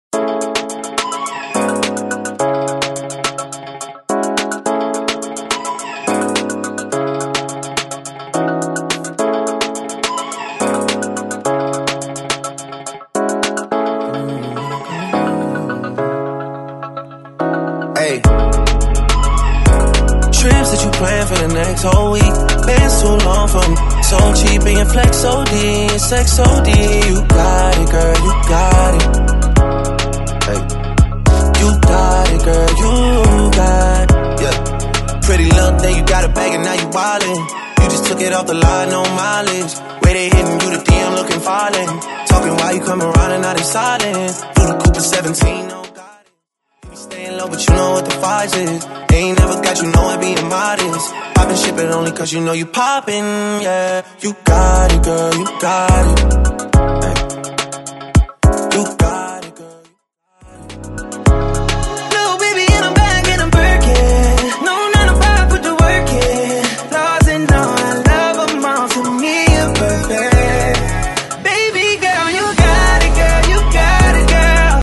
BOOTLEG , MASHUPS , R & B 106